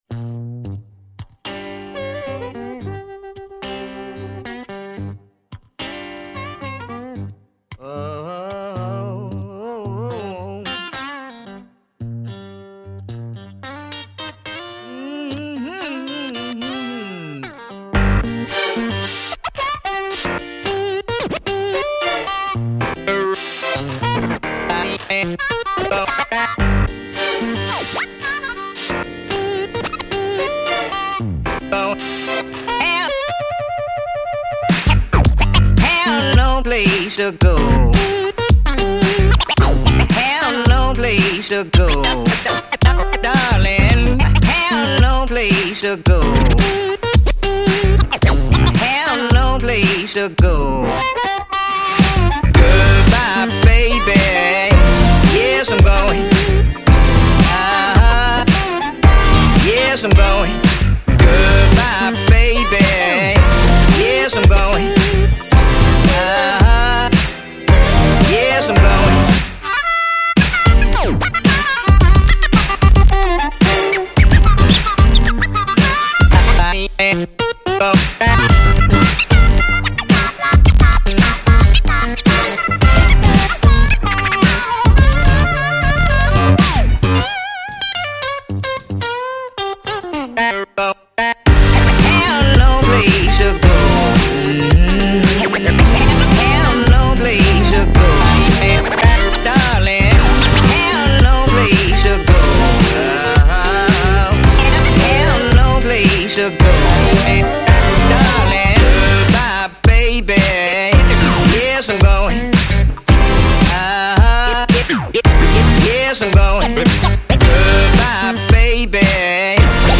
Electronic , Hip Hop , Soul Tags Best 2012